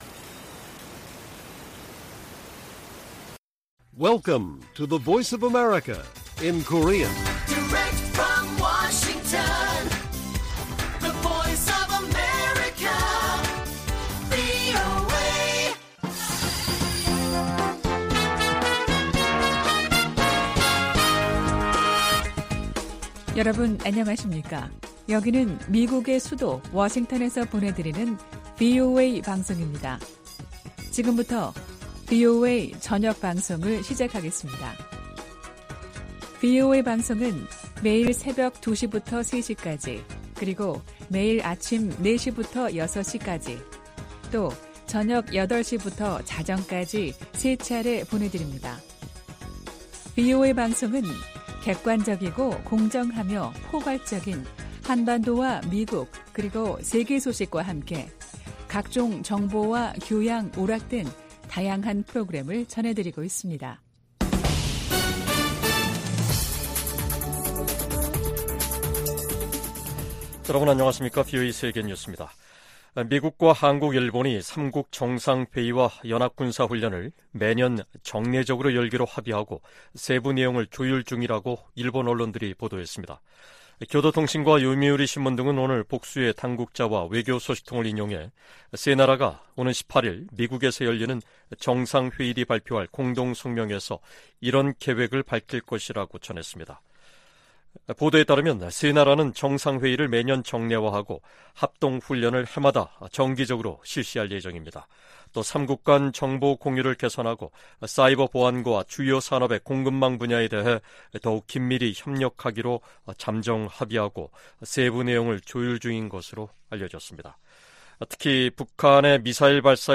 VOA 한국어 간판 뉴스 프로그램 '뉴스 투데이', 2023년 8월 11일 1부 방송입니다. 미국과 한국·일본 등이 유엔 안보리에서 북한 인권 문제를 공개 논의를 요청했습니다. 미 국방부가 북한의 추가 도발 가능성과 관련해 한국·일본과 긴밀하게 협력하고 있다고 밝혔습니다. 북한 해킹조직이 탈취한 미국 내 암호화폐 자산을 동결 조치할 것을 명령하는 미 연방법원 판결이 나왔습니다.